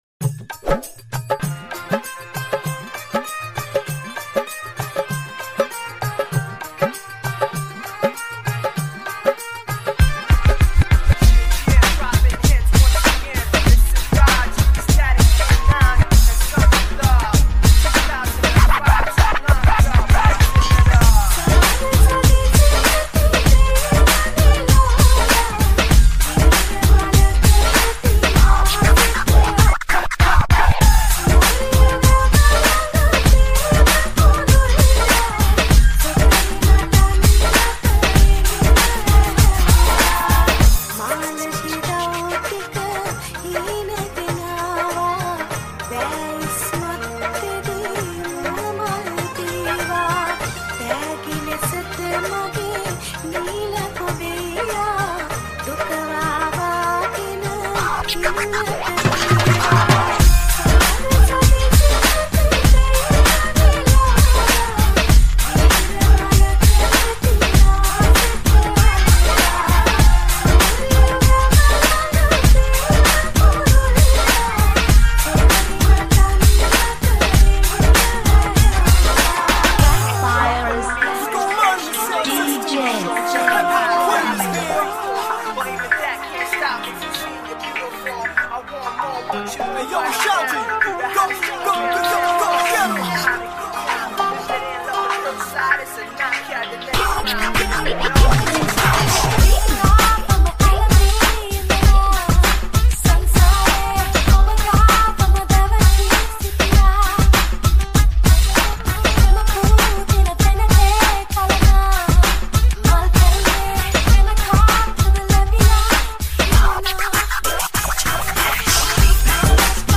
2025 New Sinhala DJ Nonstop Kawadi n Dholki